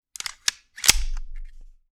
Part_Assembly_52.wav